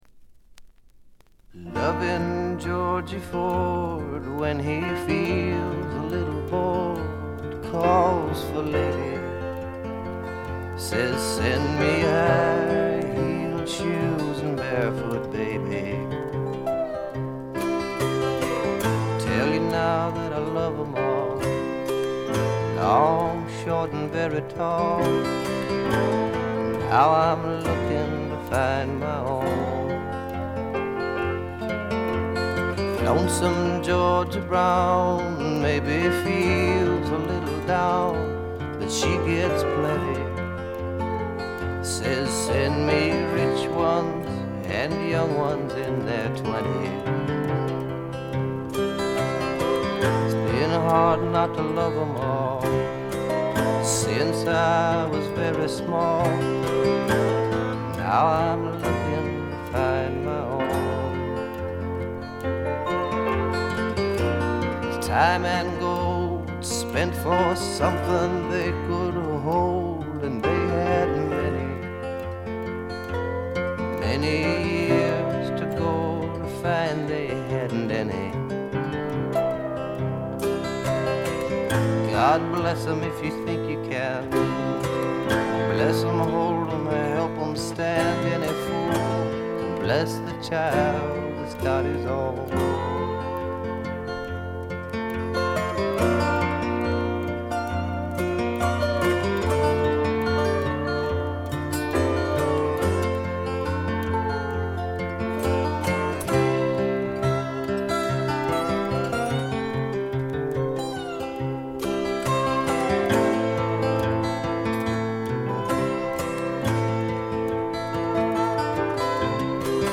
バックグラウンドノイズ、チリプチ多め大きめです。
試聴曲は現品からの取り込み音源です。